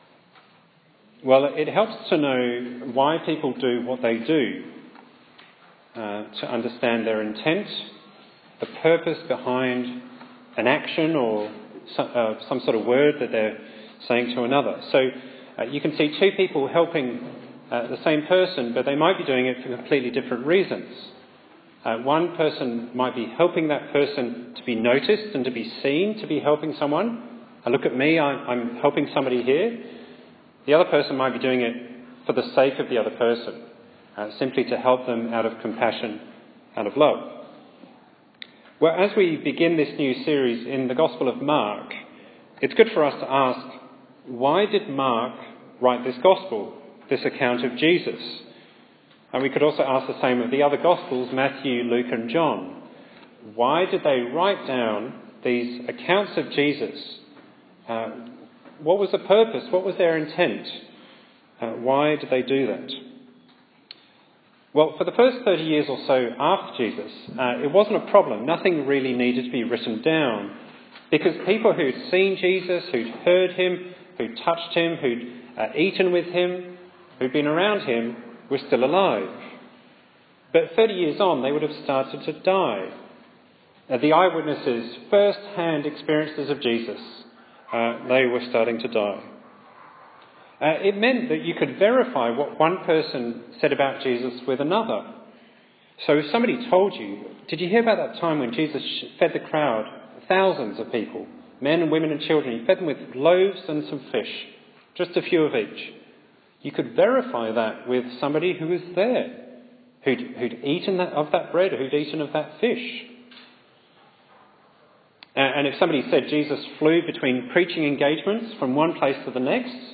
Bible Text: Mark 1:1–8 | Preacher